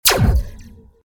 .开火3.ogg